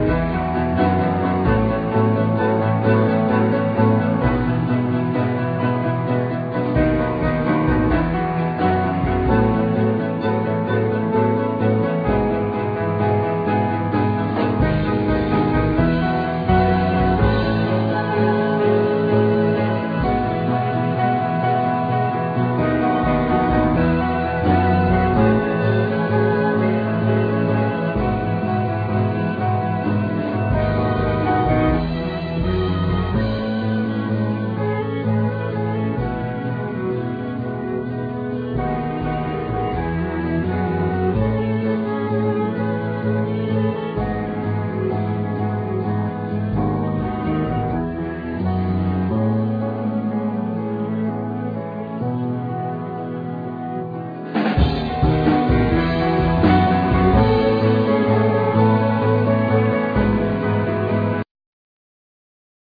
Piano,Vibes,Linen sheet
Cello
Violin